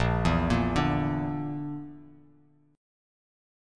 BbMb5 chord